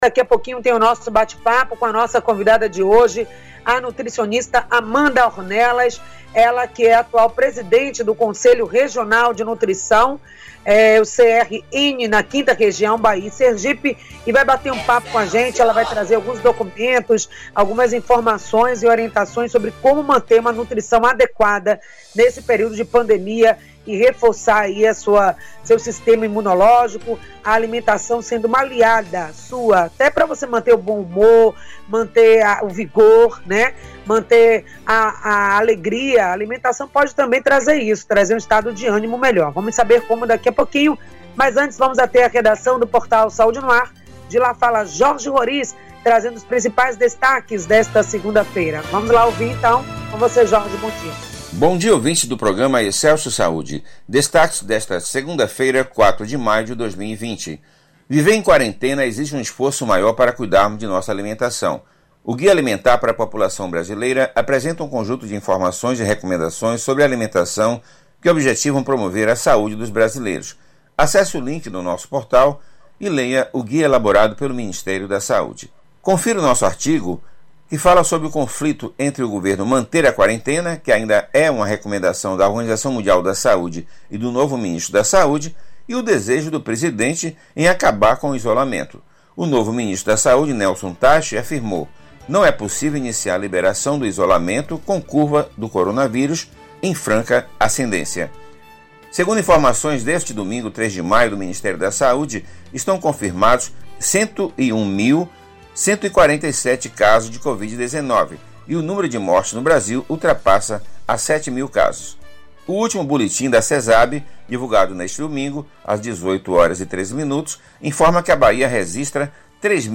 Tema da entrevista: Alimentação saudável em tempos de quarentena